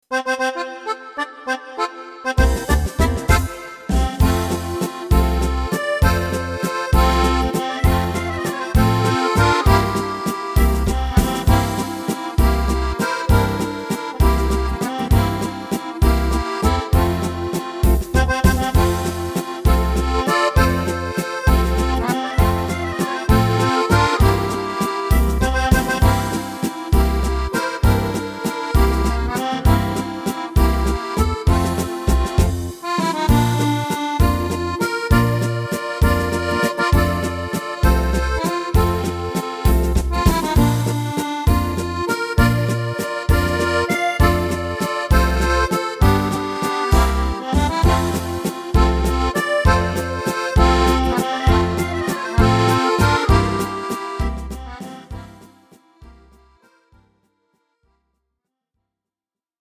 Tempo: 198 / Tonart: F – Dur